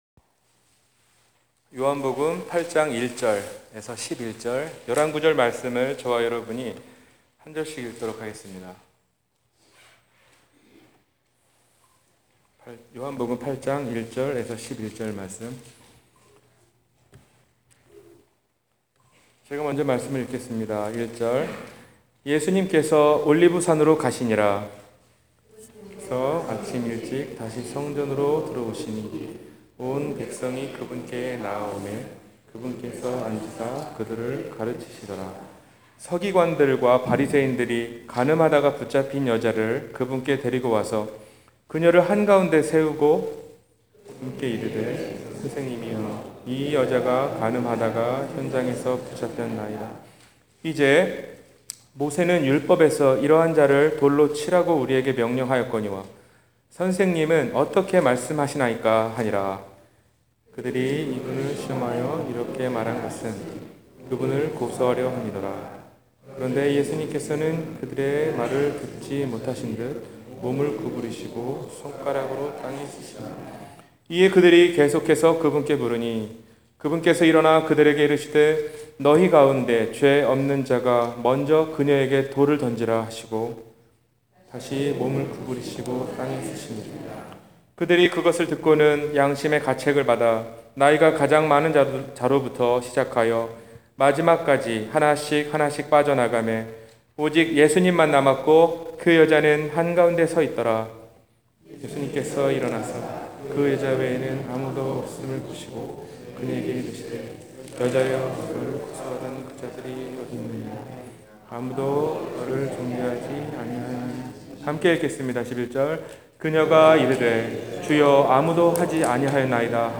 완전한 용서 – 주일설교